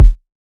99Sounds x Monosounds - Kick - 012 - D#.wav